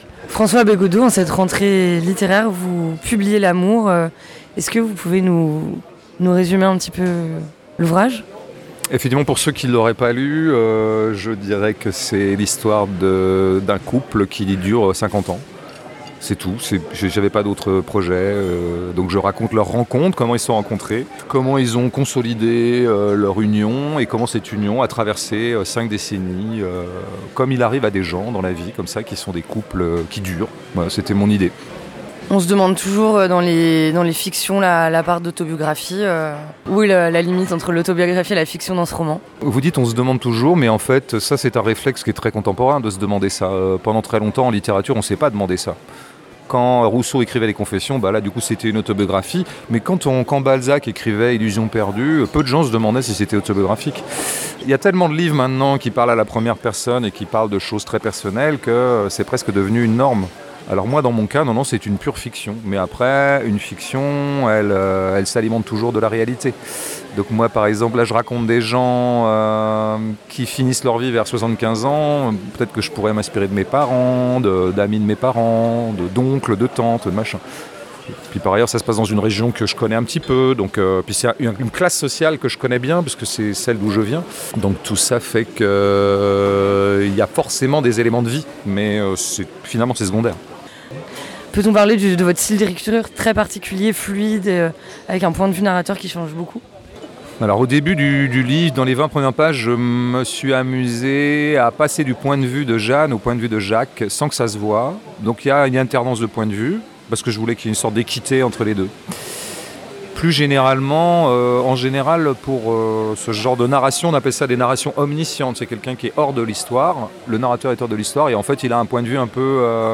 Correspondances à Manosque 2023 : François Bégaudeau